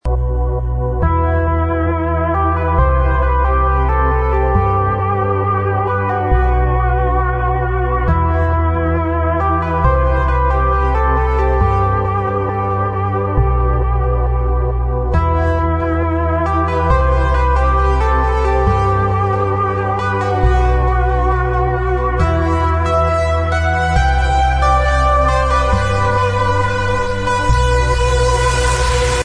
ID on this epic trance track please!